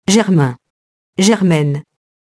germain, germaine [ʒɛrmɛ̃, -ɛn] adjectif (lat. germanus, frère)